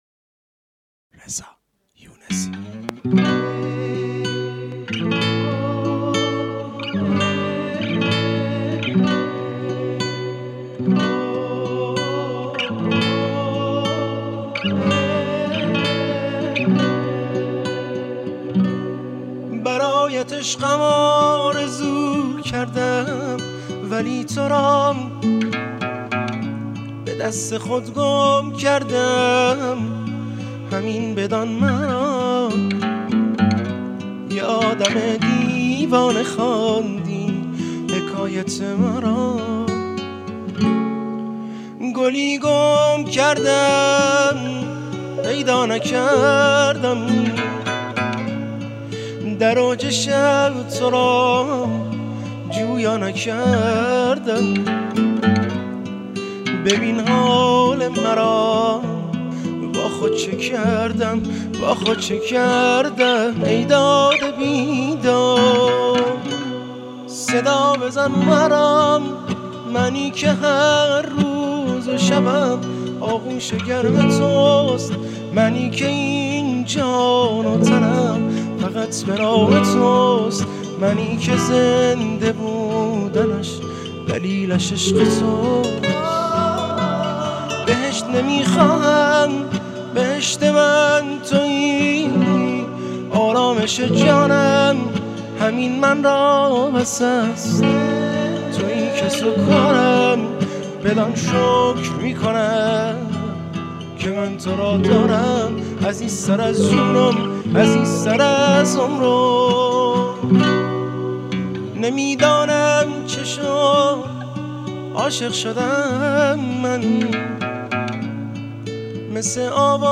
آهنگهای پاپ فارسی
با کیفیت خوب و عالی